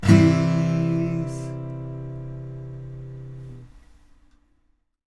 若干ですが、倍音がふくよかに聴こえることと思います。